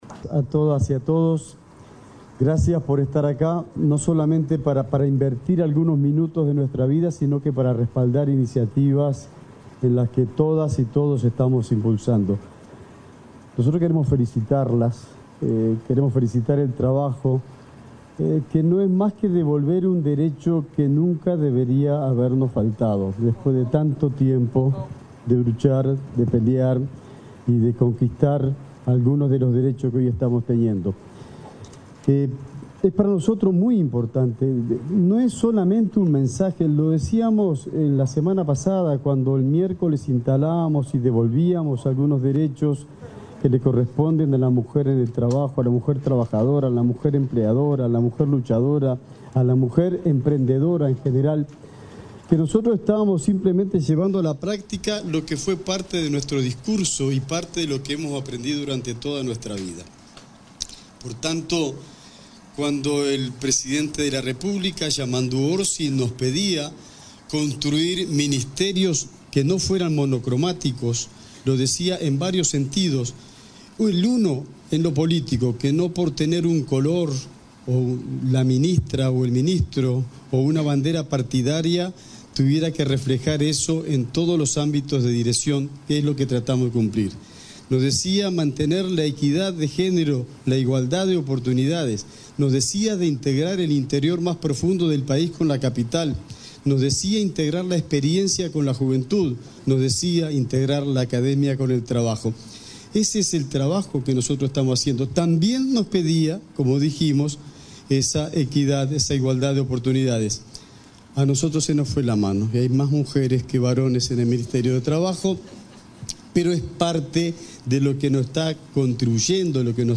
Palabras del ministro de Trabajo y Seguridad Social, Juan Castillo
El ministro de Trabajo y Seguridad Social, Juan Castillo, participó, este 19 de marzo, en la reinstalación de la Comisión Tripartita para la Igualdad